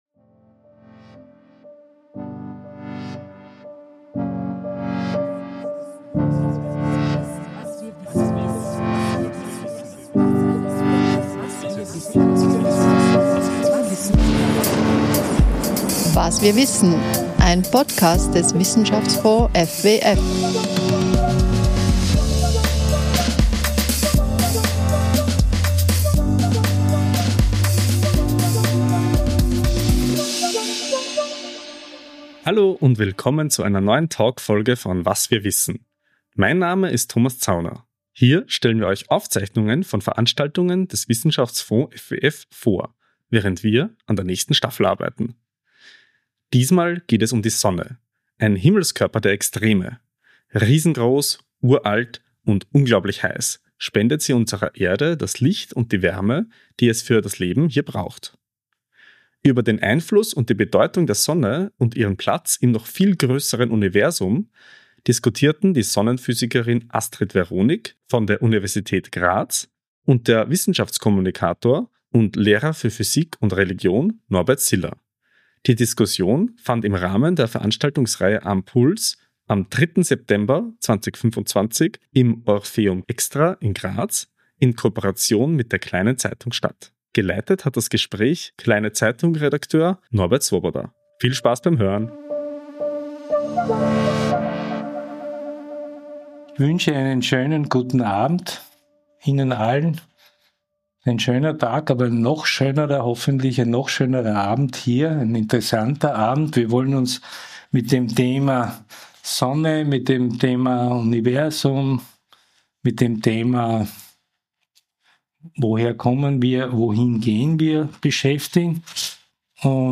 Diese Veranstaltung fand im Orpheum Extra in Graz in Kooperation mit der Kleinen Zeitung statt.